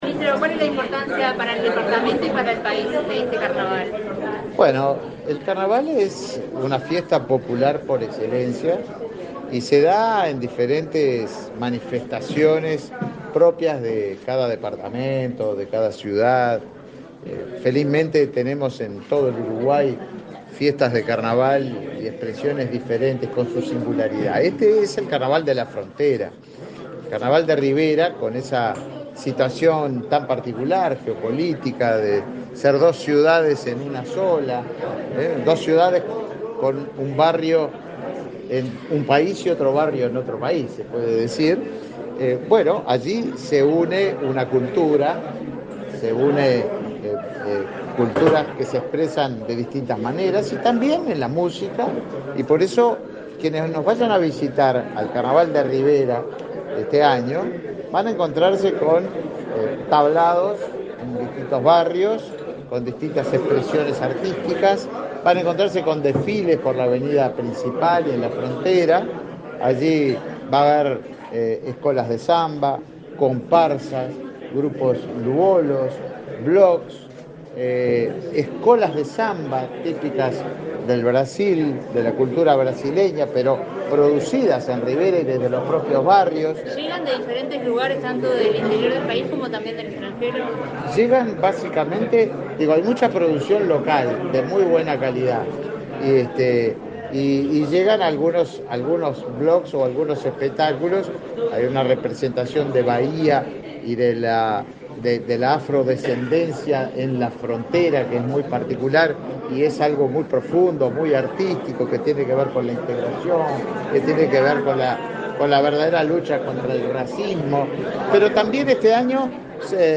Declaraciones a la prensa del ministro de Turismo, Tabaré Viera
Tras participar en el lanzamiento del Carnaval de Rivera, este 7 de febrero, el ministro Tabaré Viera realizó declaraciones a la prensa.